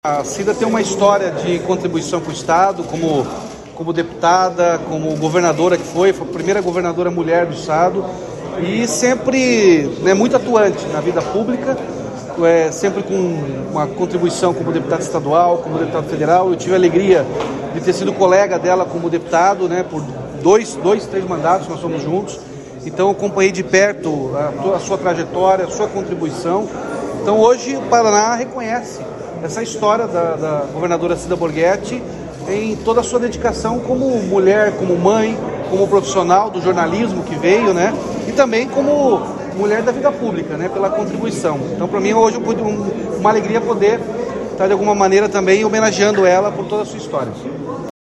Sonora do governador Ratinho Junior sobre a entrega do título de cidadã honorária do Paraná a Cida Borghetti